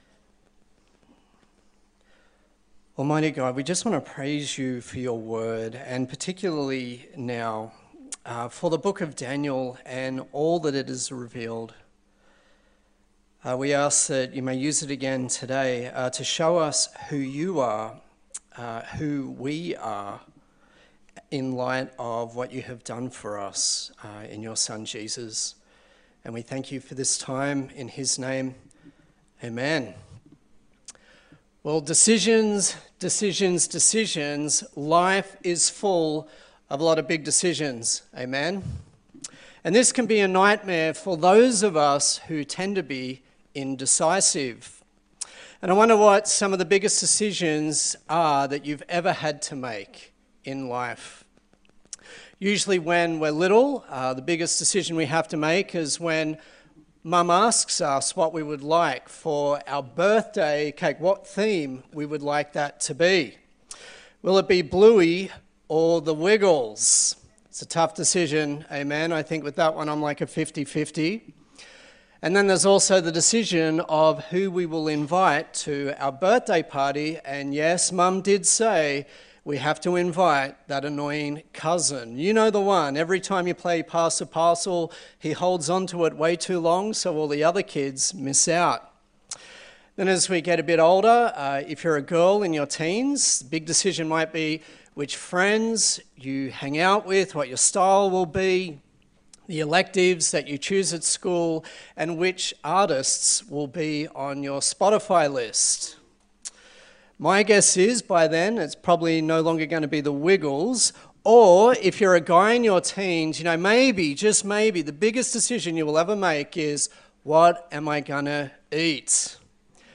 Daniel Passage: Daniel 12 Service Type: Morning Service